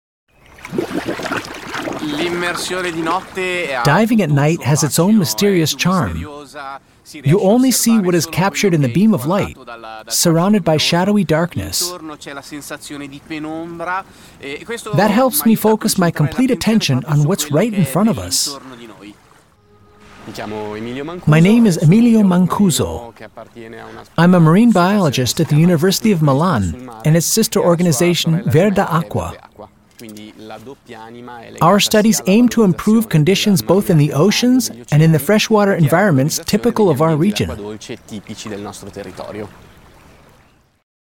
US-Amerikaner, wohnhaft in Deutschland, English Native Speaker, Image-Filme, Erklär-Videos, spreche auch Deutsh
mid-atlantic
Sprechprobe: Sonstiges (Muttersprache):